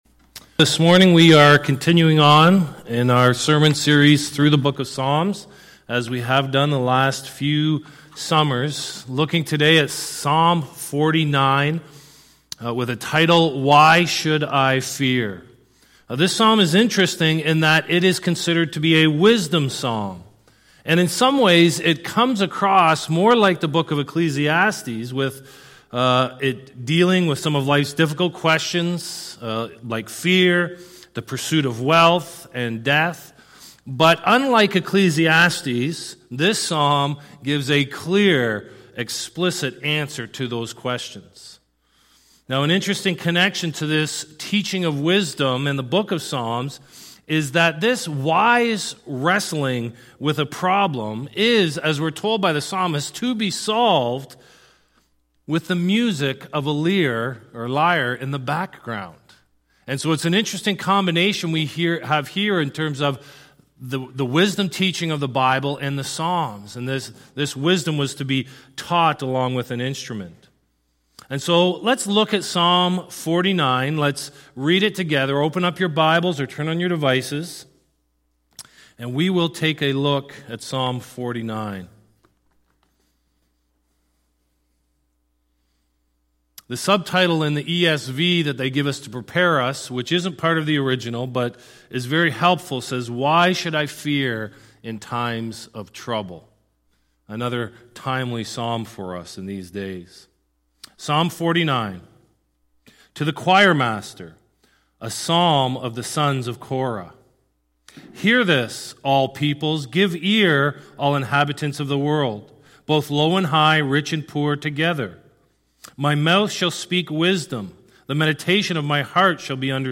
Sermon Archives